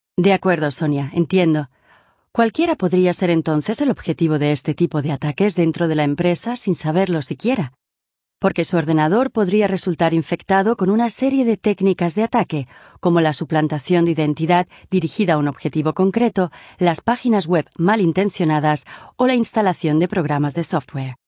I am a professional Spanish female voice talent with long-time experience.
Sprechprobe: eLearning (Muttersprache):
My maternal language is Castilian-Spanish (excellent pronunciation, no regional accent).
My voice is clear and pleasant and can evoke and reflect a wide range of moods and tones: dramatic, ironic, distant, gentle, forceful, direct or suggestive.